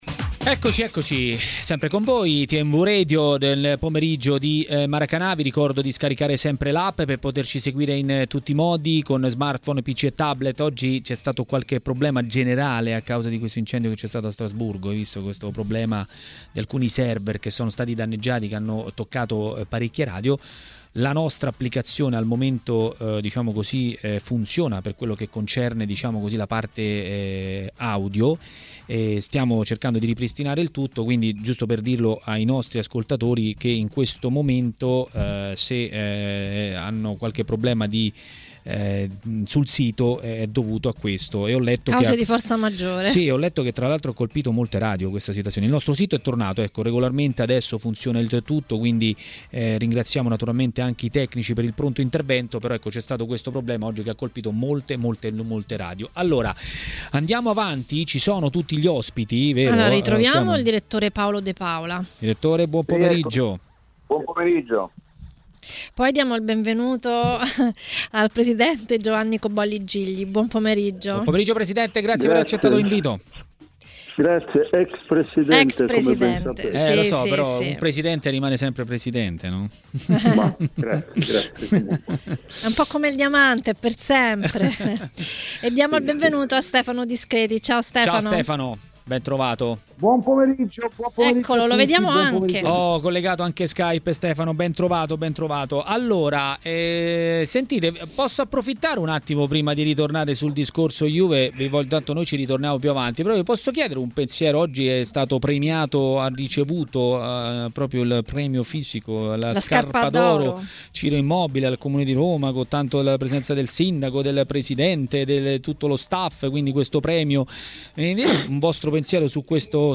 Per parlare dell'uscita dalla Champions della Juventus a TMW Radio, durante Maracanà, è intervenuto l'ex presidente Giovanni Cobolli Gigli.